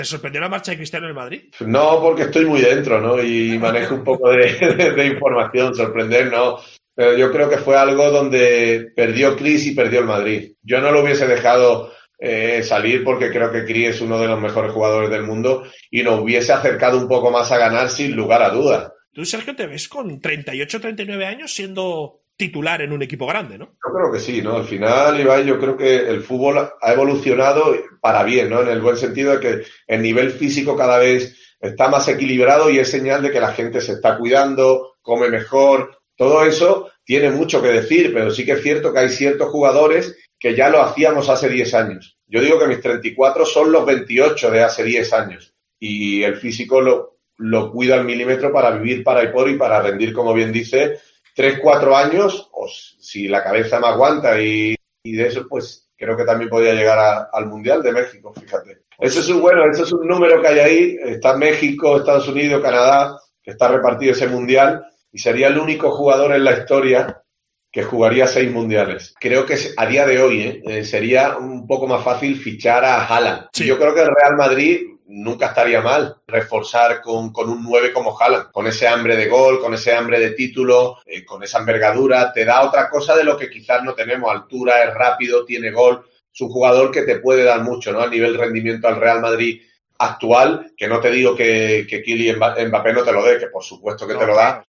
AUDIO: El capitán del Madrid, en una entrevista con Ibai Llanos, reconoció que ficharía a Haaland antes que a Mbpappé y que quiere jugar el Mundial de 2026.